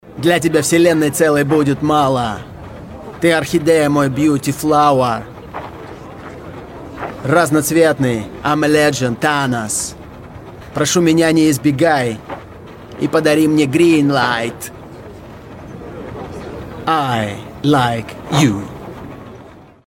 Акапелла